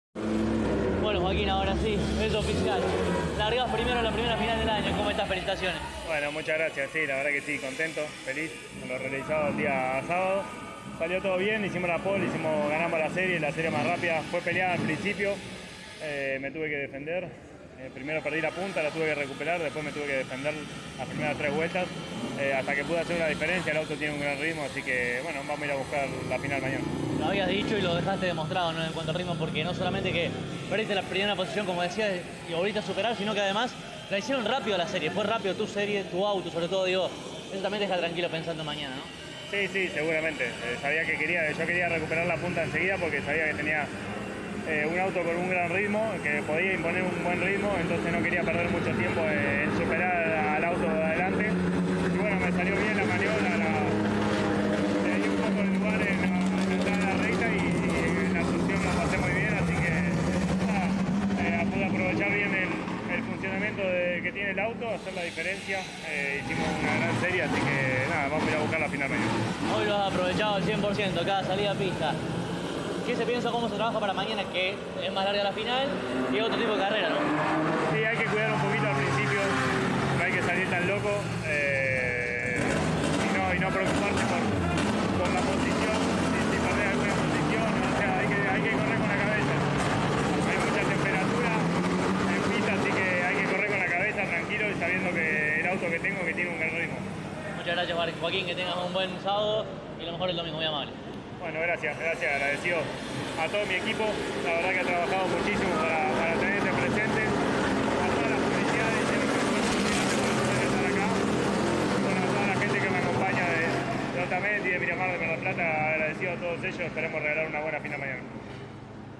en diálogo con CÓRDOBA COMPETICIÓN: